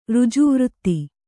♪ řjuvřtti